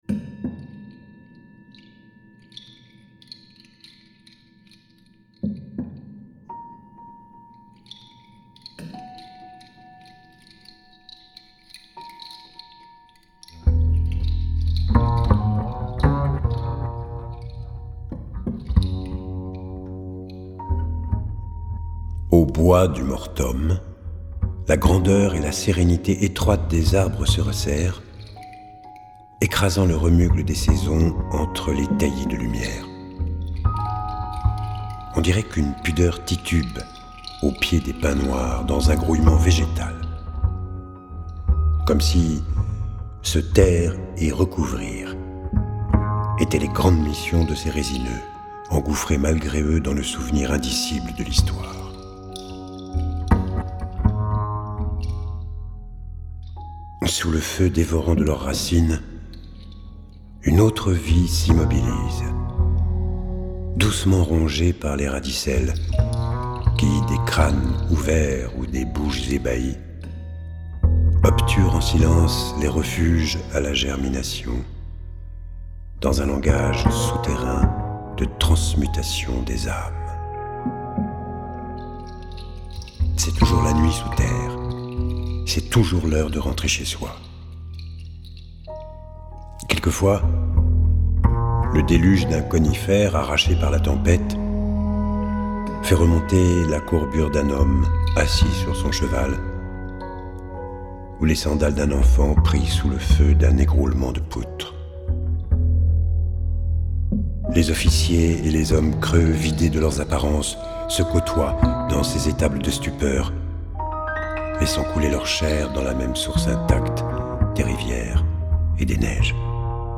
Poésie sonore